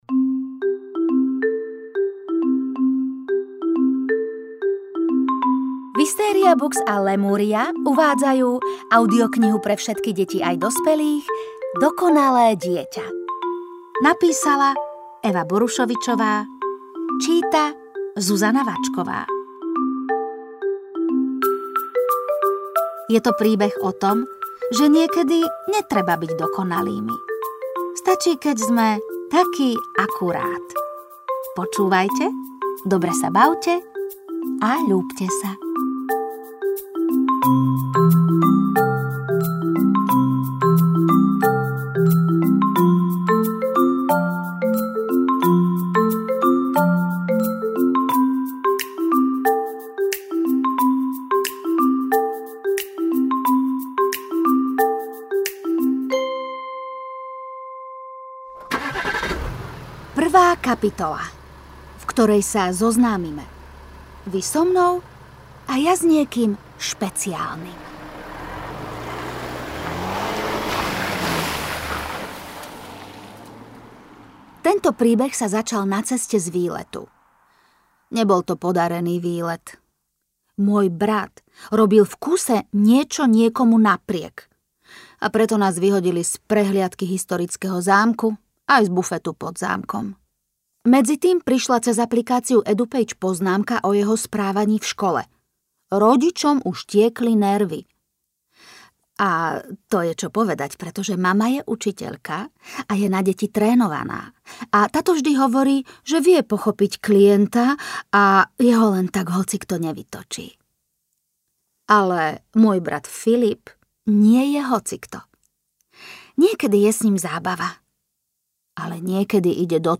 Dokonalé dieťa audiokniha
Ukázka z knihy